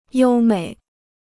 优美 (yōu měi): graceful; fine.